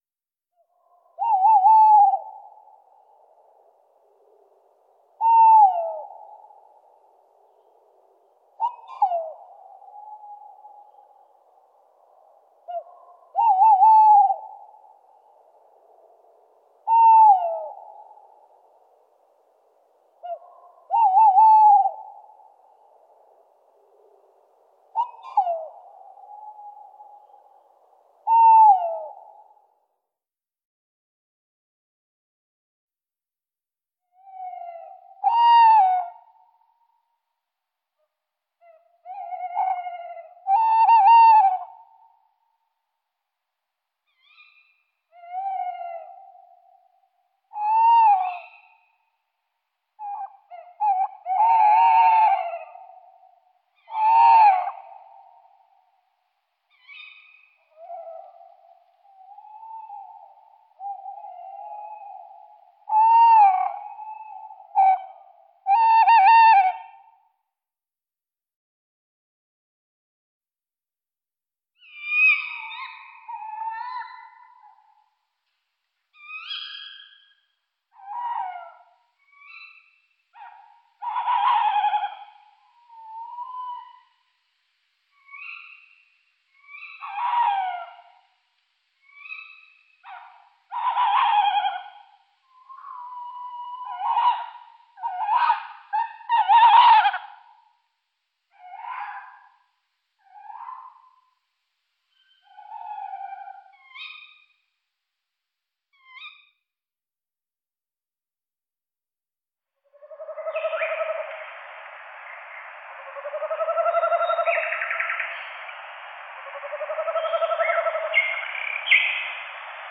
Bosuil
Het geluid van het mannetje is een beetje spookachtig.
bosuilzang.mp3